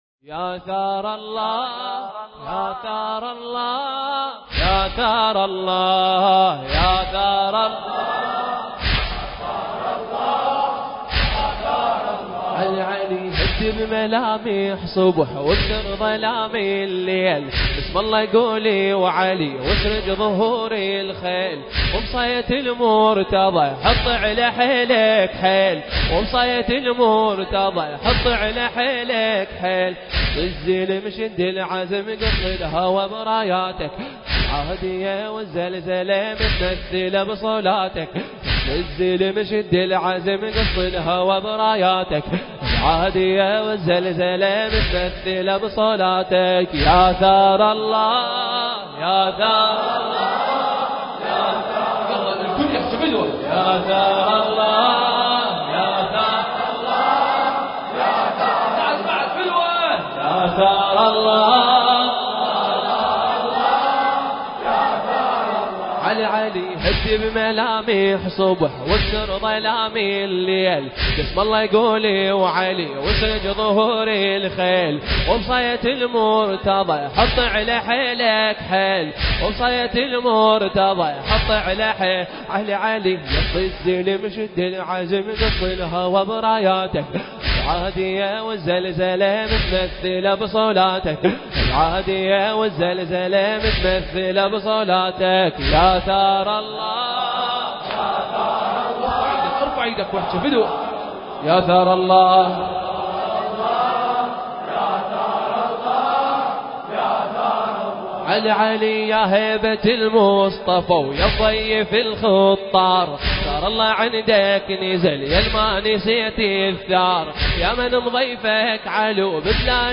المكان: مضيف سلطان بني هاشم (عليه السلام) – الناصرية
ذكرى أربعينية الإمام الحسين (عليه السلام)